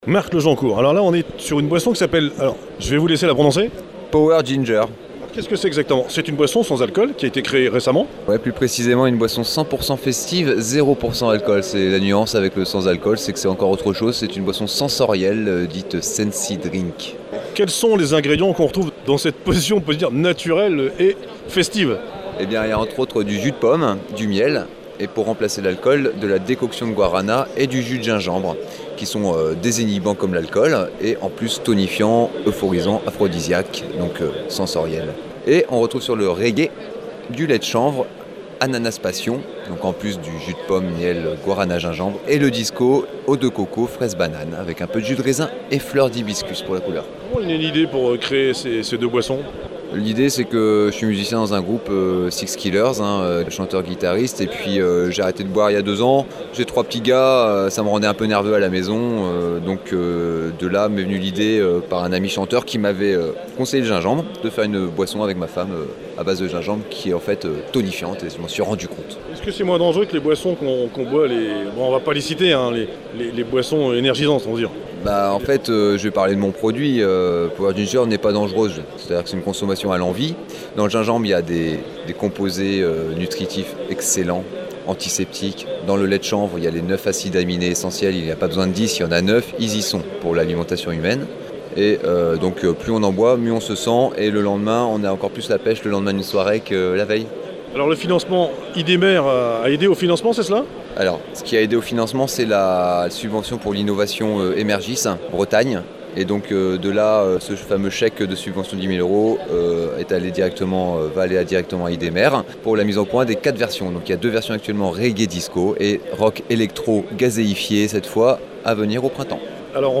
Présentation